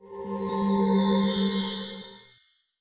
01_blood.mp3